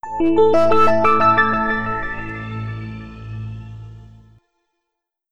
Samsung Galaxy S40 Startup.wav